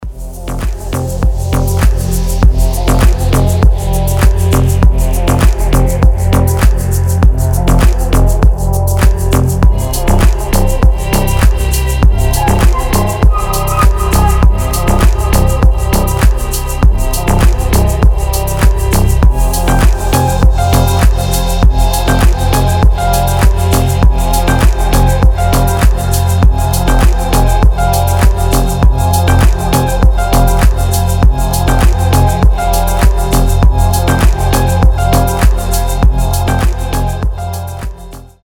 • Качество: 320, Stereo
deep house
красивая мелодия
релакс
расслабляющие
Chill